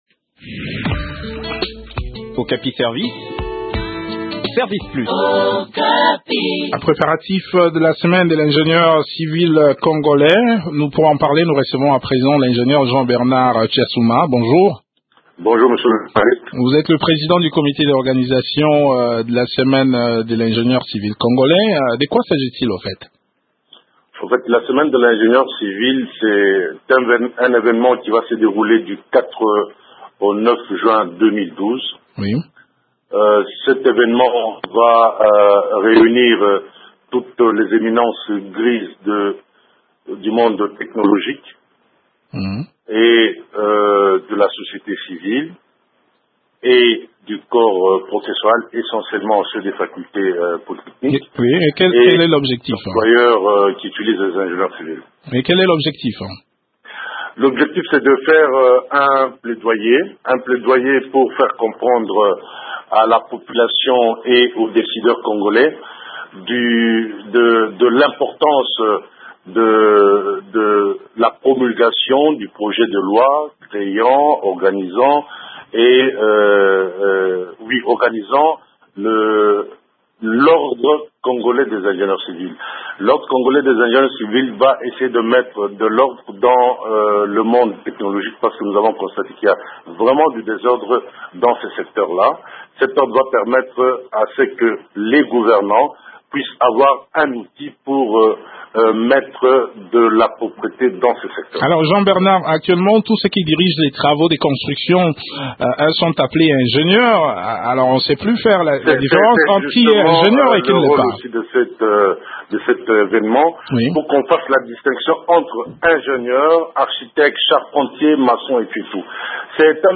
Selon quelques techniciens interviewés, l’Etat congolais n’accorde pas une place de choix à leur profession.